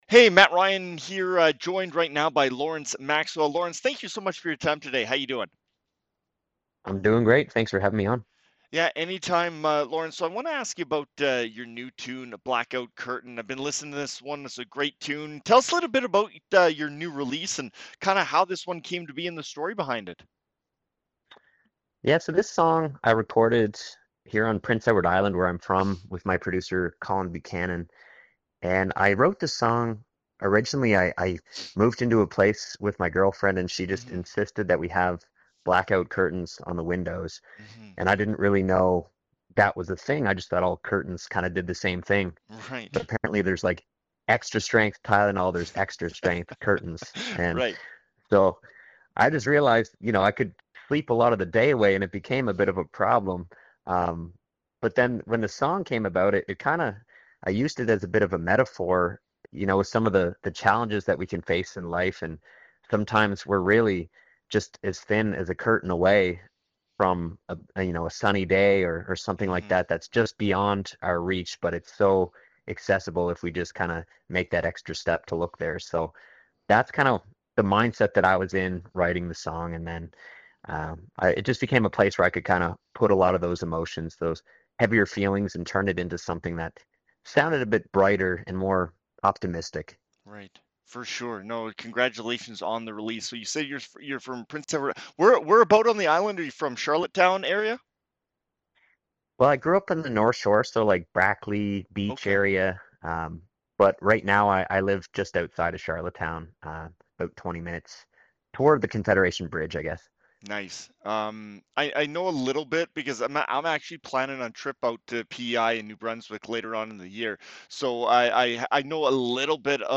joins us on the phone to talk new music and what’s on the way